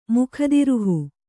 ♪ mukhadiruhu